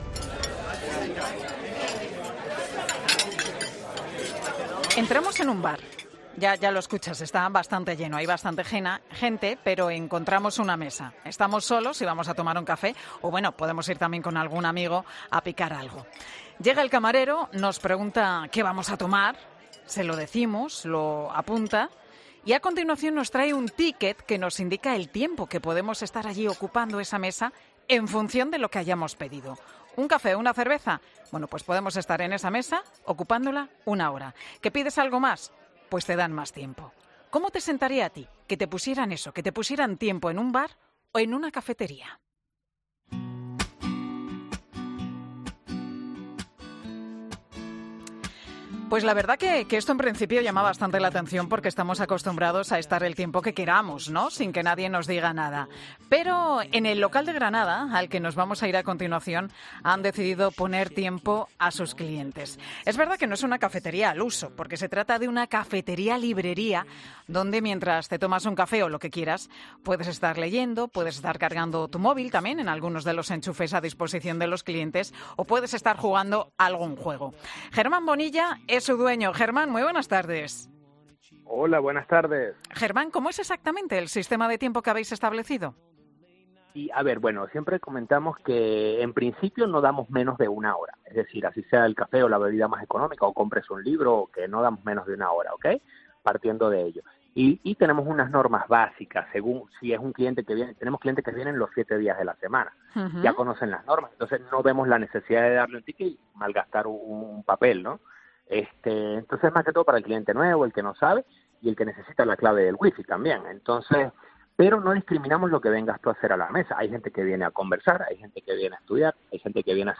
Pilar García Muñiz descubre, en 'Mediodía COPE' las mejores historias.